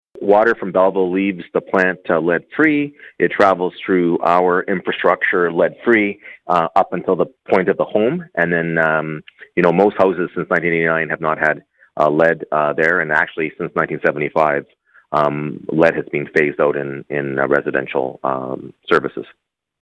Mayor Mitch Panciuk told Quinte News the city’s drinking water leaves the plant lead-free.